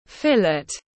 Thịt phi lê tiếng anh gọi là fillet, phiên âm tiếng anh đọc là /ˈfɪl.ɪt/
Fillet /ˈfɪl.ɪt/